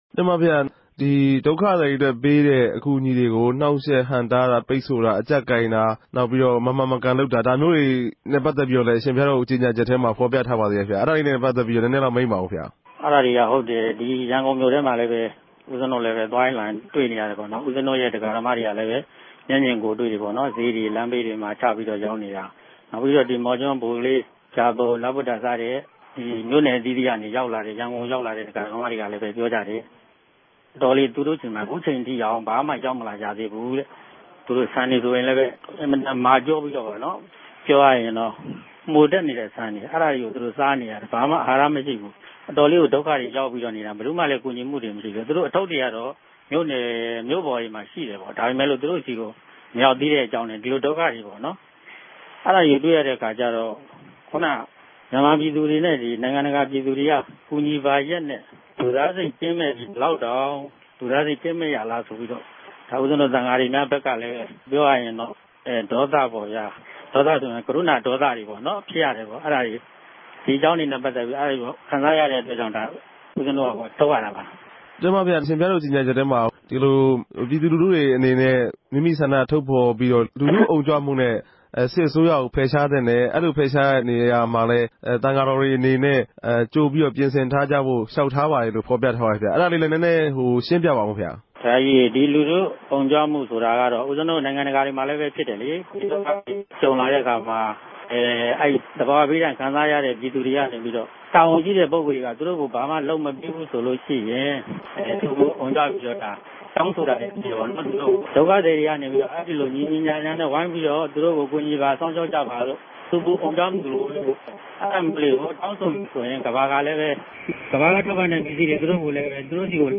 လ္တေွာက်ထားမေးူမန်းခဵက်။